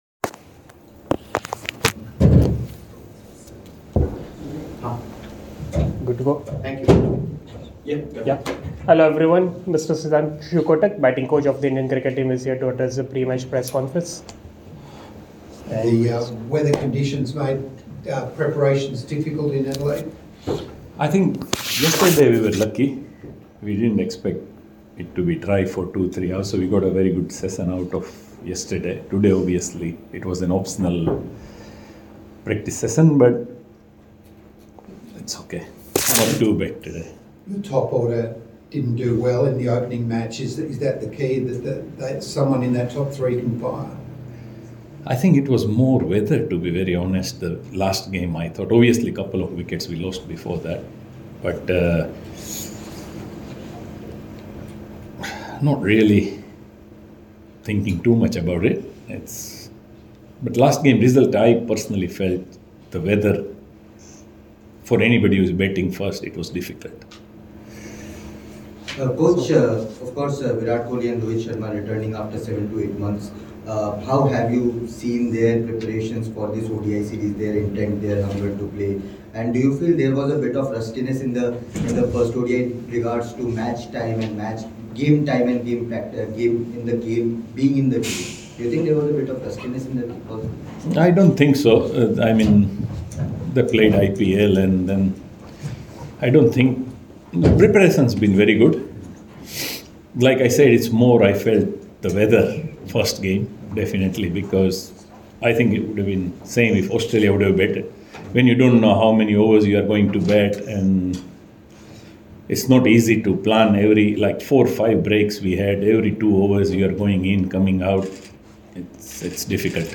Addresses Press Conference Ahead of 2nd ODI at Adelaide Oval